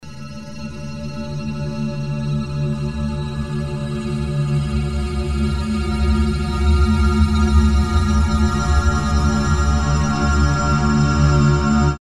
"Металлический" звук